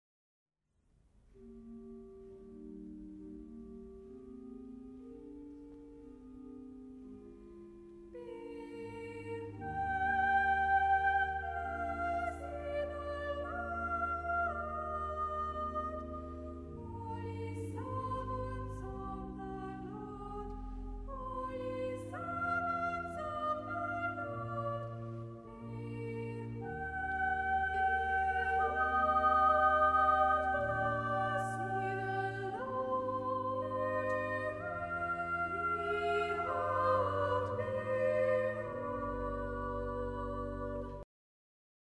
Two-part